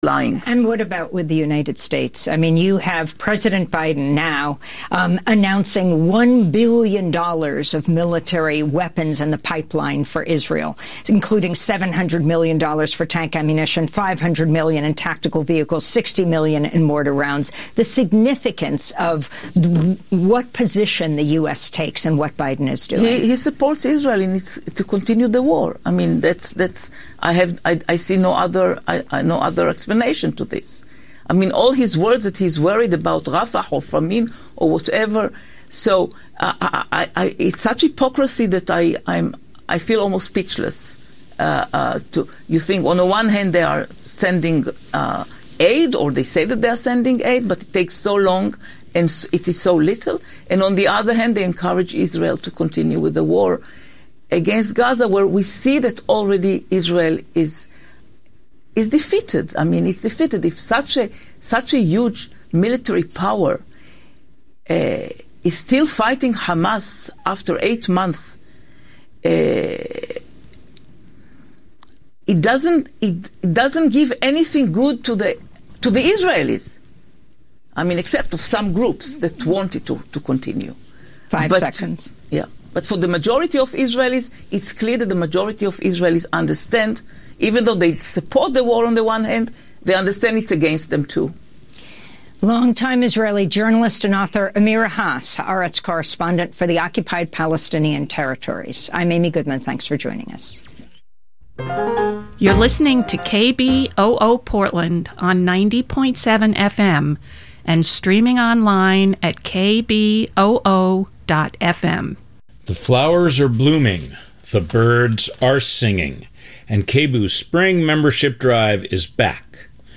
The Gap is talk radio aimed at bridging the generational divide.